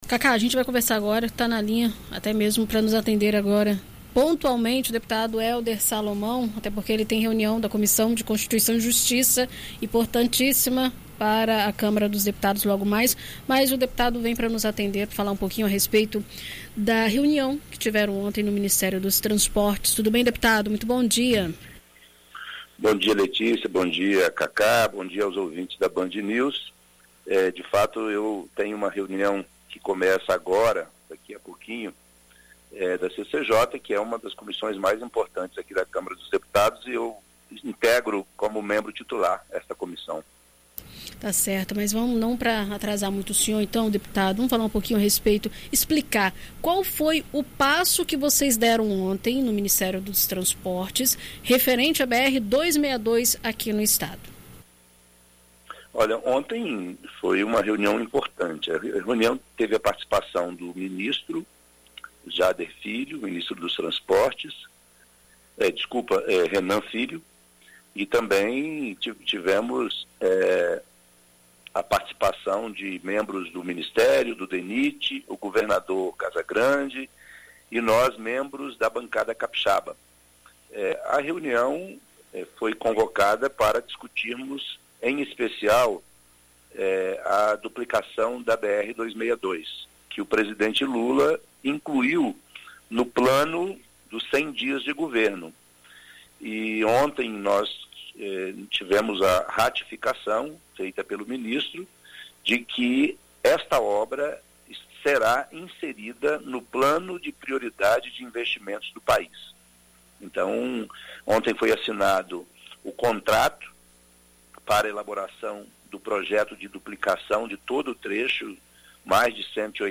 Nesta quarta-feira (14), o deputado federal Hélder Salomão (PT), em entrevista à Rádio BandNews Espírito Santo, detalhou como será feita a duplicação que vai demandar recurso da ordem de aproximadamente R$ 4 bilhões para ser concluída.